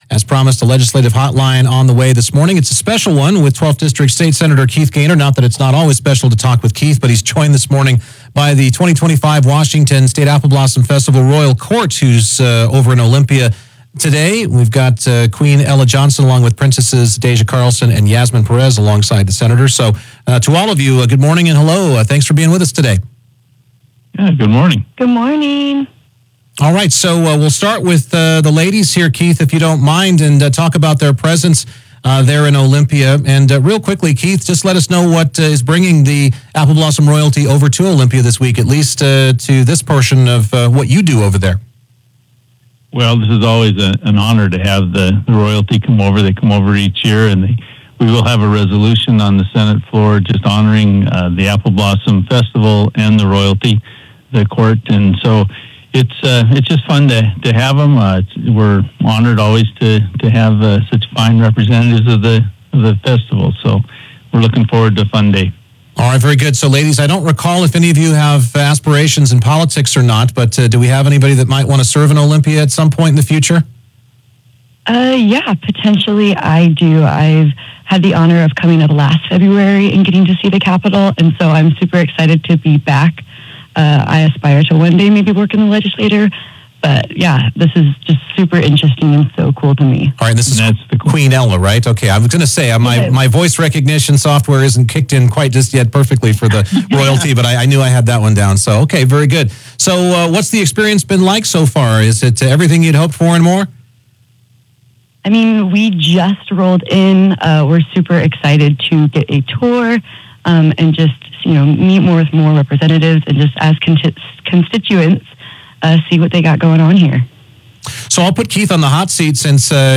KPQ interviews Sen. Keith Goehner and the 2025 Apple Blossom Royal Court during their Capitol visit. They discussed special education, mental health, healthcare access, energy projects, housing, and transportation.